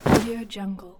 دانلود افکت صوتی صدای پارچه 6
Synthetic Fabric Drop 6 royalty free audio track is a great option for any project that requires domestic sounds and other aspects such as a banner, bed and blanket.
Sample rate 16-Bit Stereo, 44.1 kHz